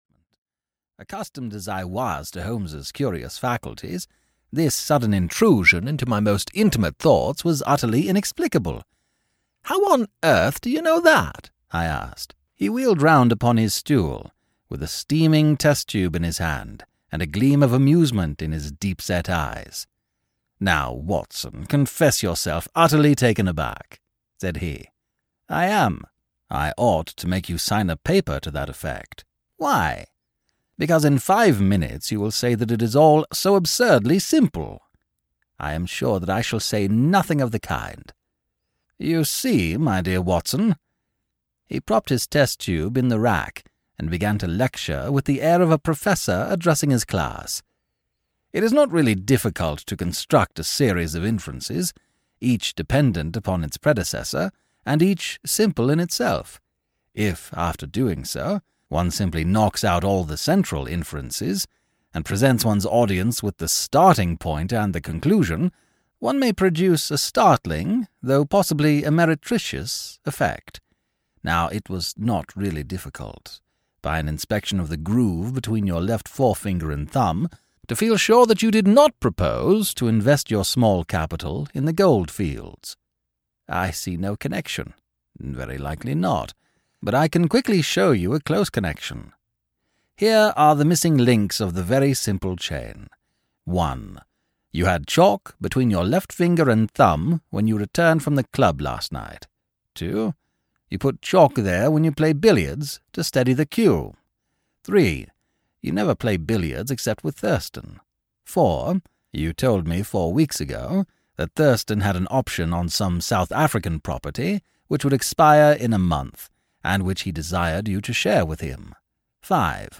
audiokniha
Ukázka z knihy